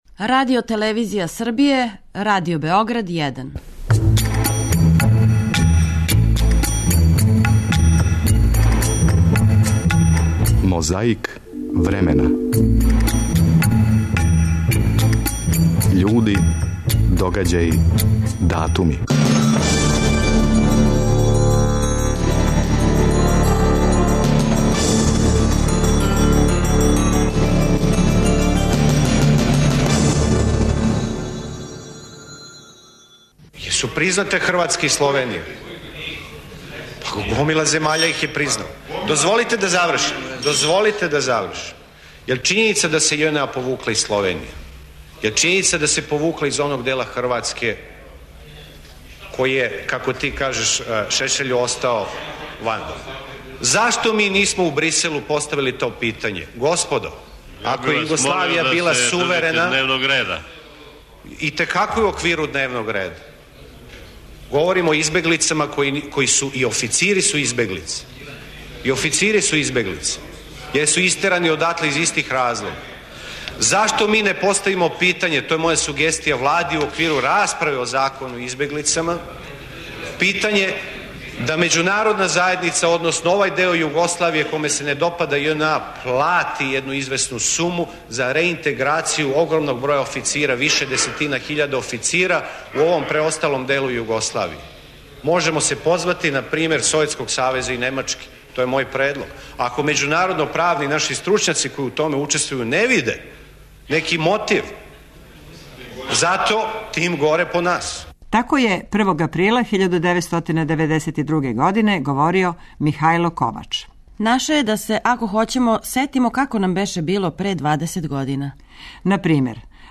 Први програм Радио Београда преносио је догађај директно.
Поменуте тонске материјале и неколико других снимака Радио Београда који носе ране априлске датуме слушамо ове суботе у емисији Мозаик времена.